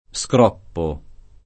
[ S kr 0 ppo ]